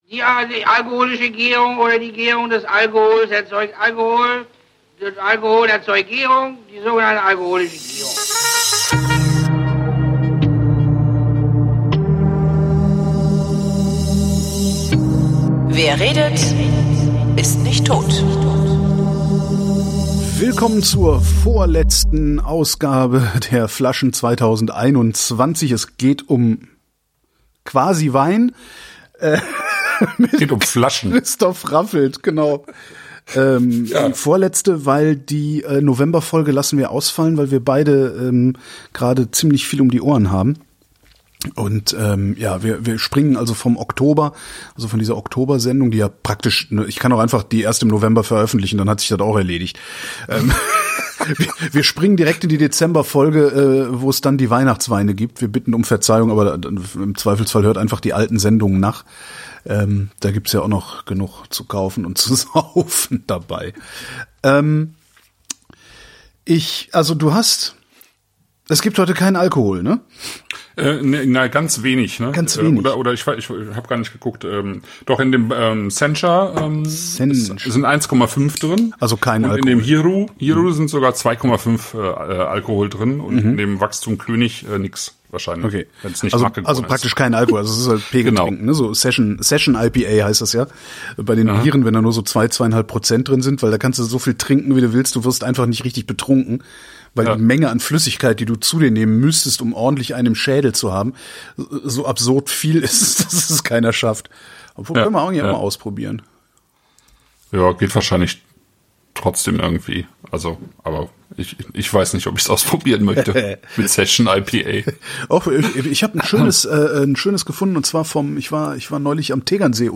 Diesmal ausnahmsweise ungeschnitten, weil die Sendung schnell veröffentlicht werden musste.